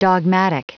Prononciation du mot dogmatic en anglais (fichier audio)
Prononciation du mot : dogmatic